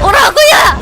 Worms speechbanks
Dragonpunch.wav